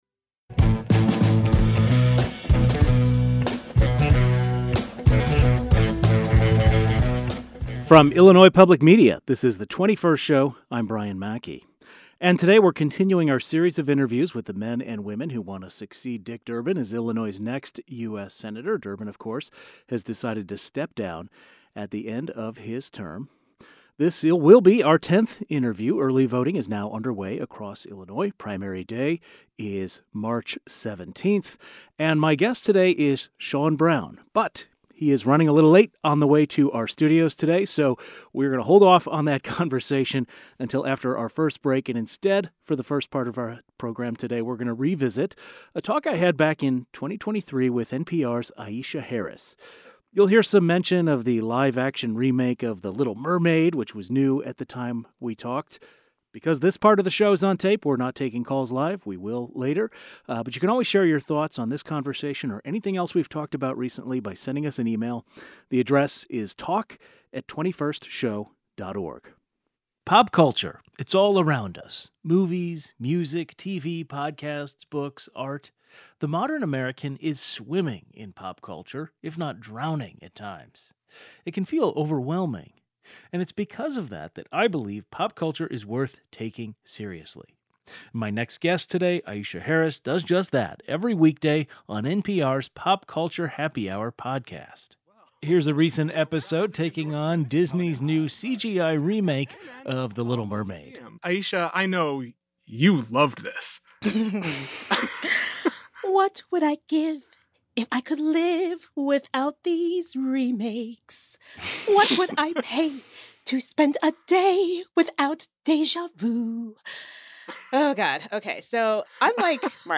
In 2023, NPR's Aisha Harris joined The 21st Show to talk about the culture that shaped her, the intersection of her identities as a Black woman and a critic, and more.